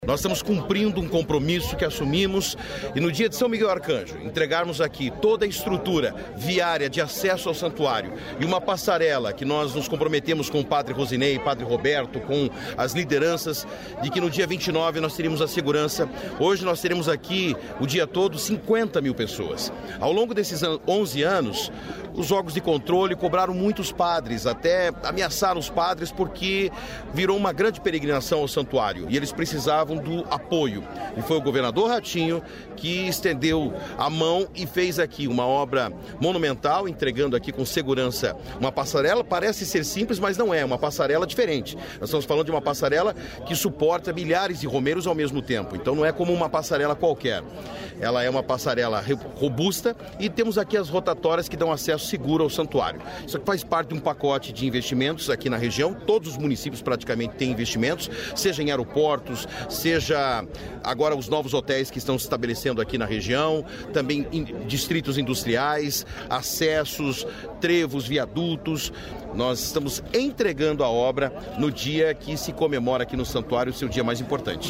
Sonora do secretário de Infraestrutura e Logística, Sandro Alex, sobre novos acessos ao Santuário São Miguel Arcanjo